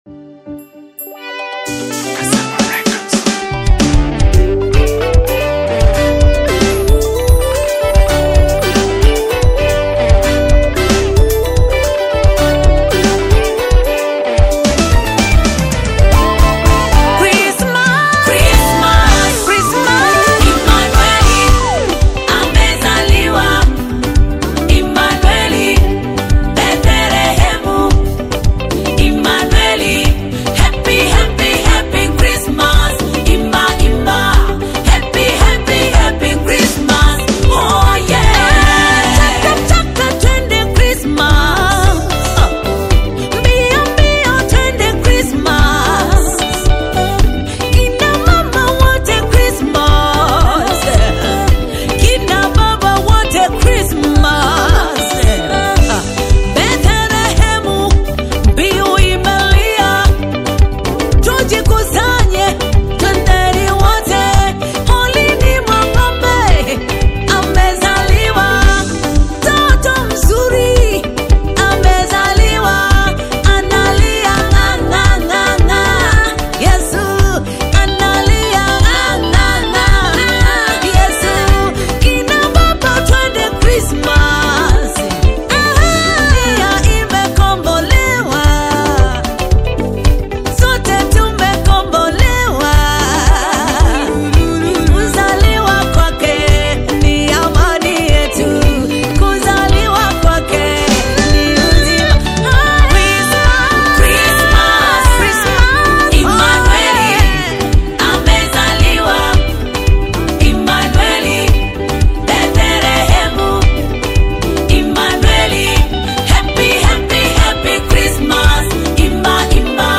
Christian gospel music